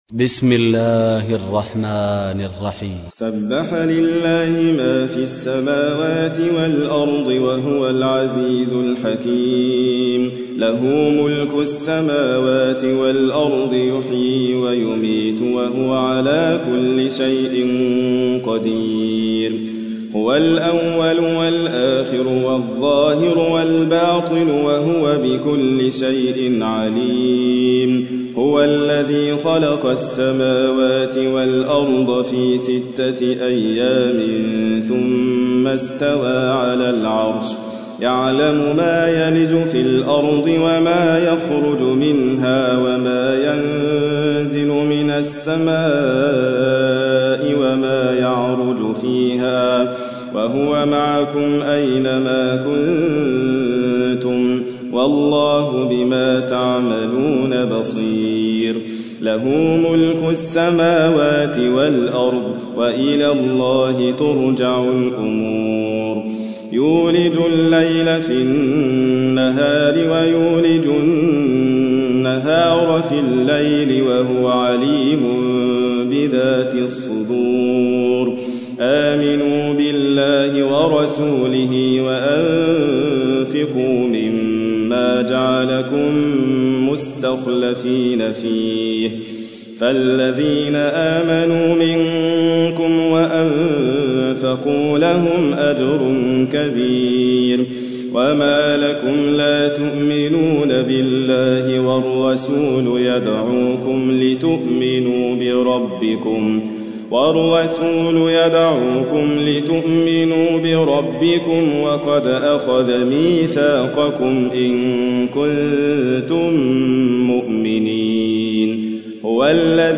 57. سورة الحديد / القارئ